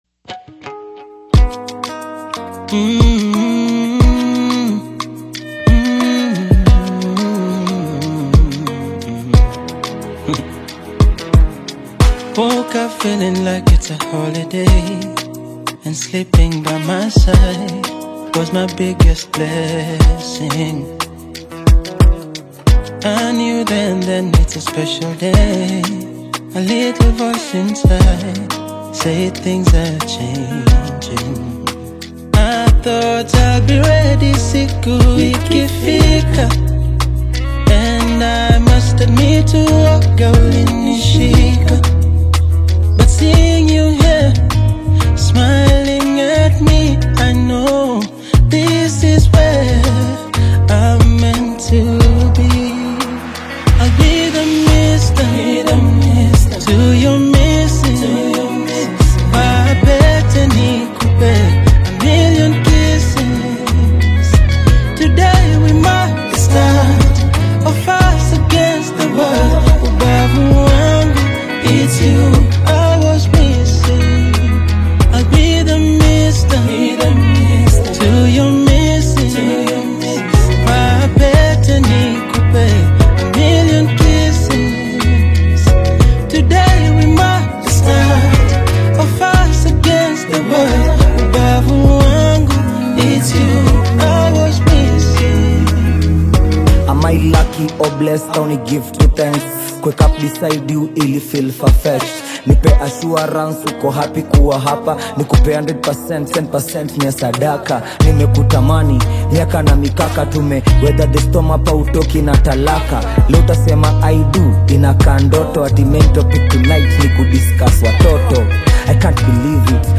AudioKenyan Music
romantic Afro-Pop single